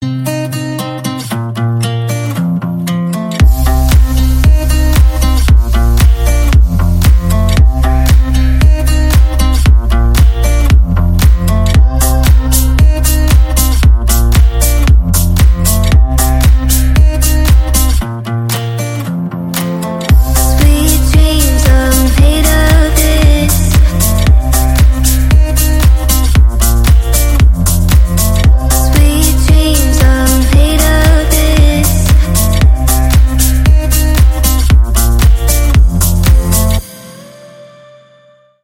Deep House , Chill House